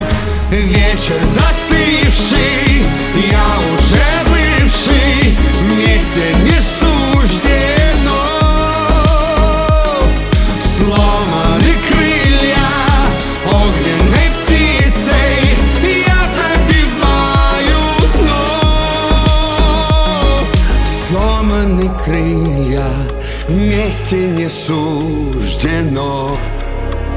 эстрада
грустные , битовые , печальные , красивый мужской голос